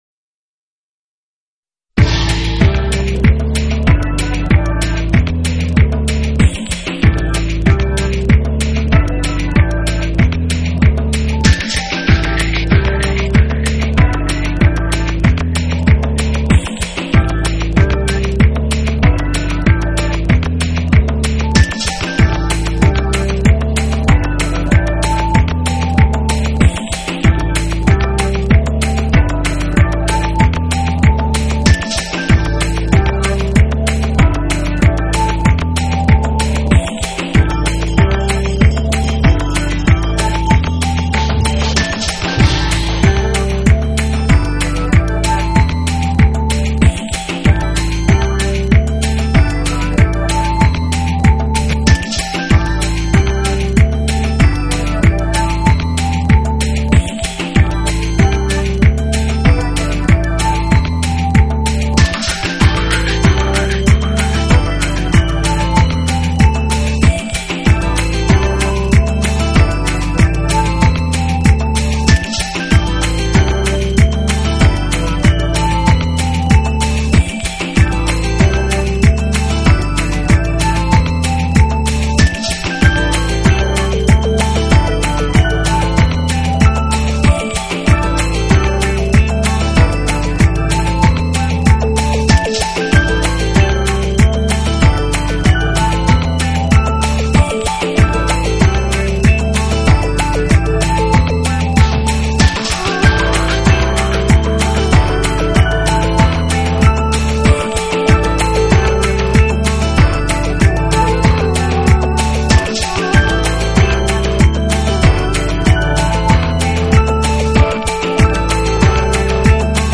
专辑歌手：纯音乐
一张令你听后感到清新，宁静，忘我，释放的迷幻发烧音乐天碟。
融合了中东迷幻与西藏的神秘感，穿插了佛教的宁静，加入了现在
最流行的电子而制作而成的现代最富争议的流行轻音乐。
一流的录音效果，是今世纪不可错过的经典唱片，试音必备。